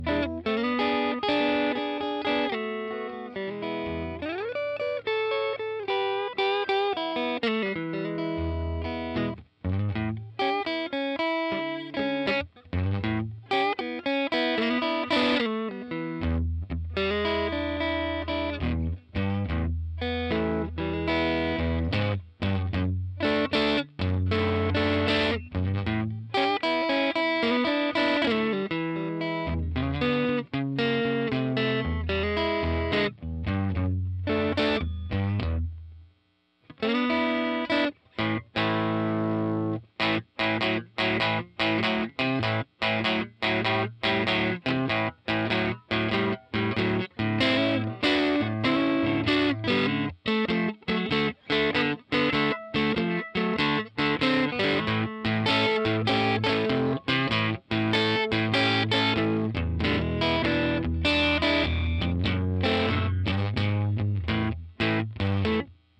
Recorded using SM57 and Tone Tubby 212 bomb.